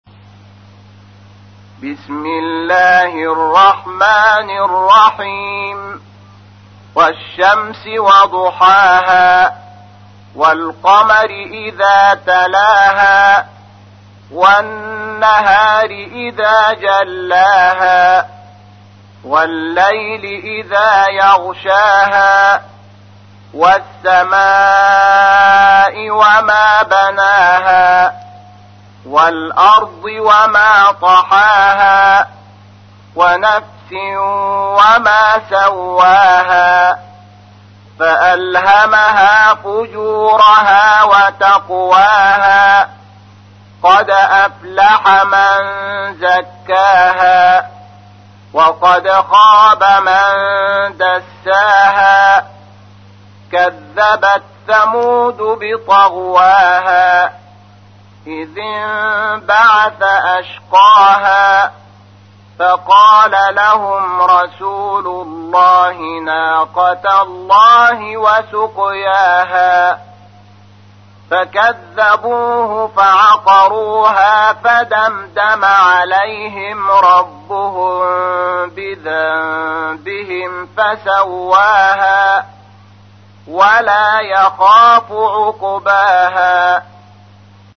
تحميل : 91. سورة الشمس / القارئ شحات محمد انور / القرآن الكريم / موقع يا حسين